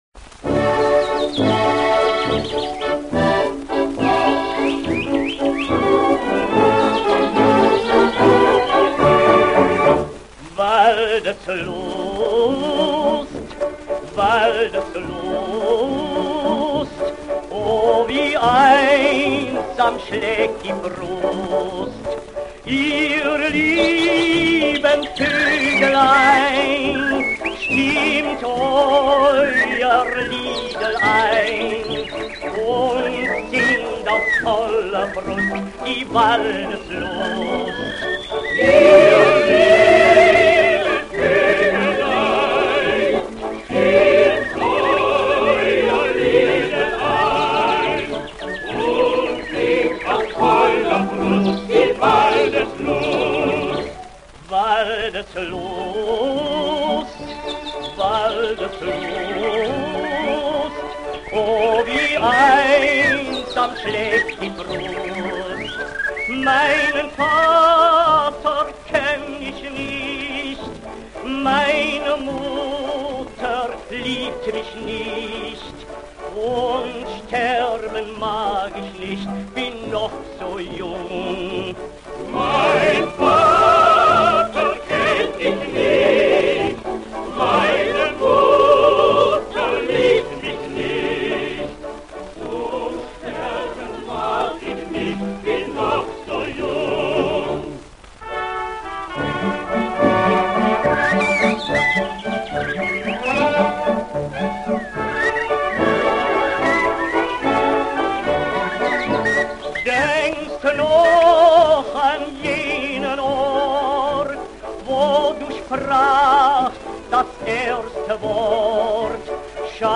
Ein Lied zur Beruhigung im mp3-Format: